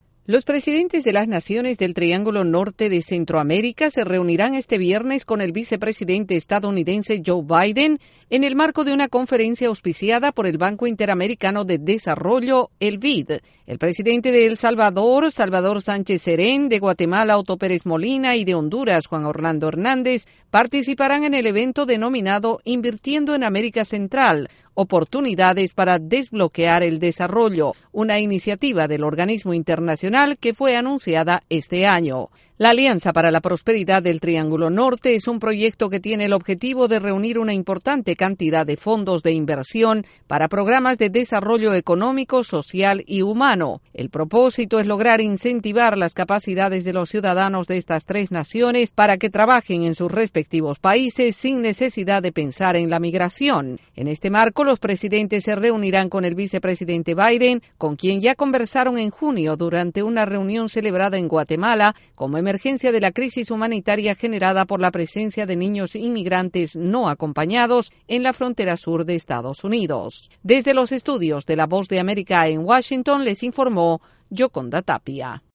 Los presidentes del Triángulo Norte de Centroamérica visitarán Washington con una importante agenda centrada en programas de desarrollo y migración. Desde la Voz de América en Washington DC informa